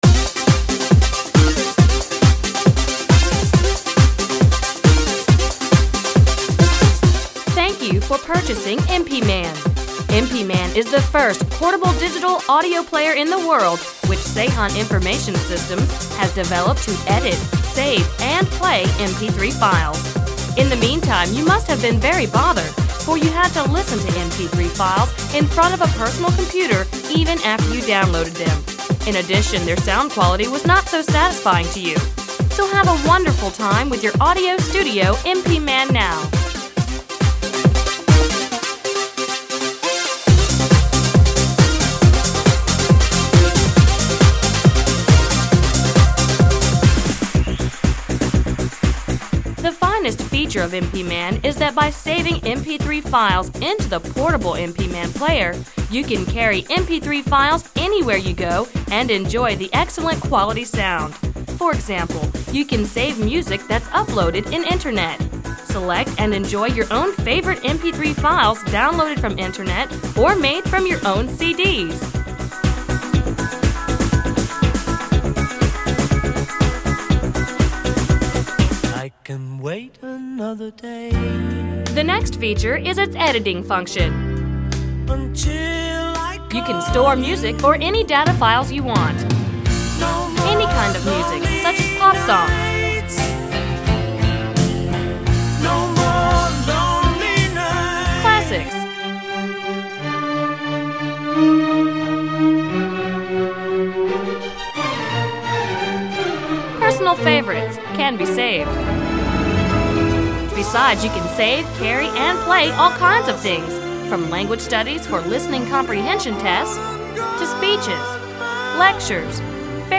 MP3 файл, где приятный женский голос (наверное это голос MPMan :-)) рассказывает о плеере, о том, зачем он нужен и т.д. Все это под музыкальное сопровождение разных стилей.
Особенно хорошо вписывается в рекламный ролик фрагмент песни Пола Маккартни со словами "Больше не будет одиноких сердец…".